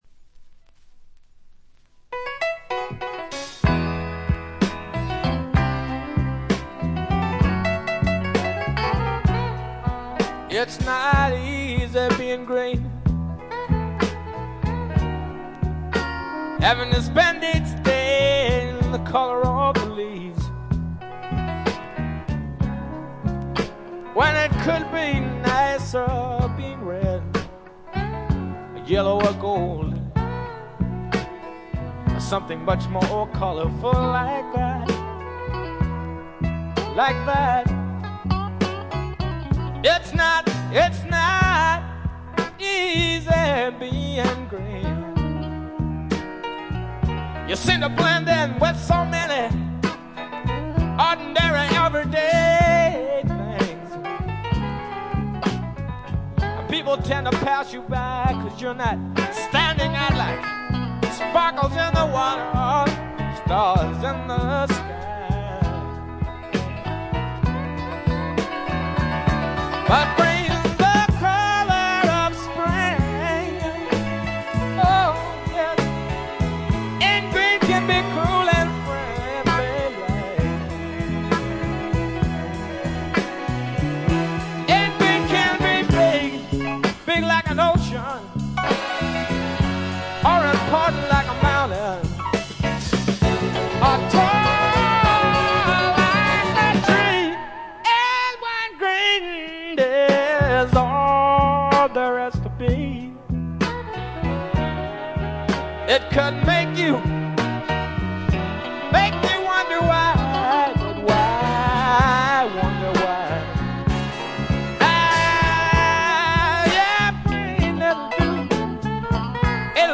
acoustic guitar, vocals